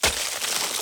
Ice Freeze 1.wav